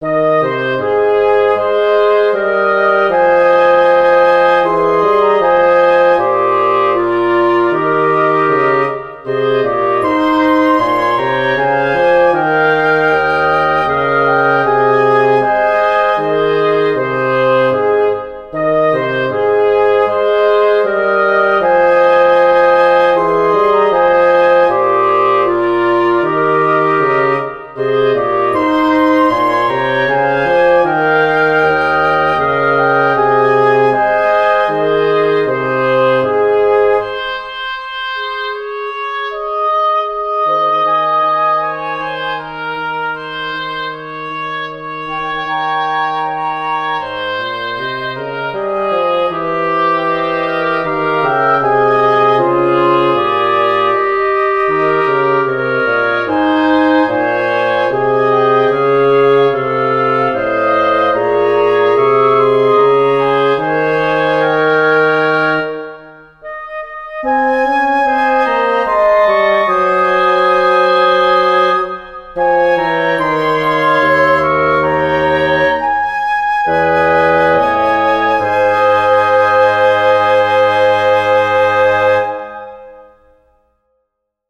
Voicing: Woodwind Q